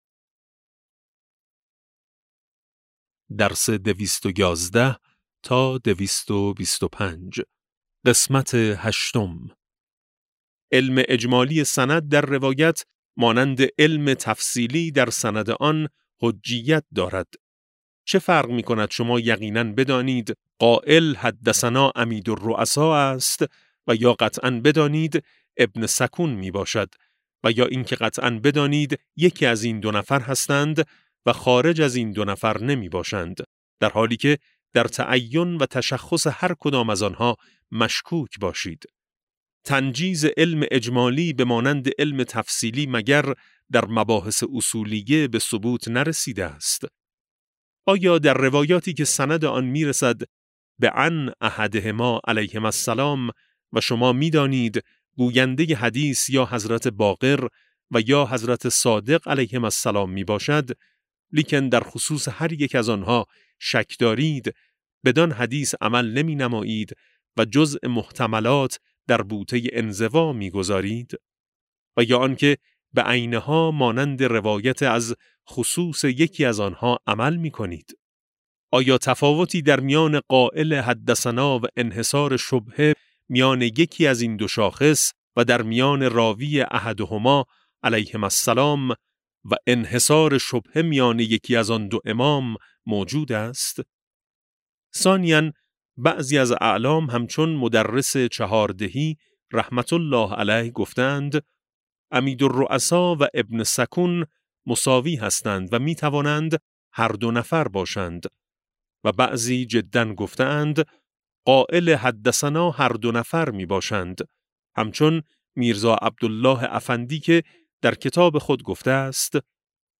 کتاب صوتی امام شناسی ج15 - جلسه8